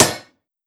Blacksmith hitting hammer 3.wav